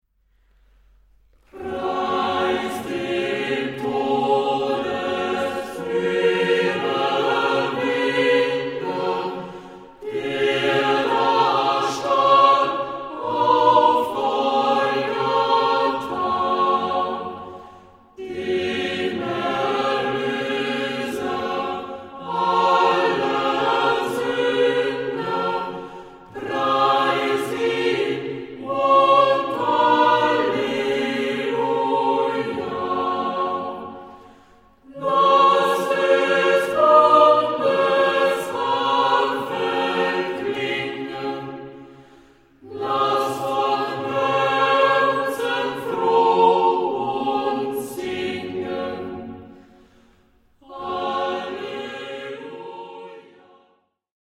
Kammerchor des Ferdinandeums
(Osterlied) aufgezeichnet in Mühlbach/Tauferertal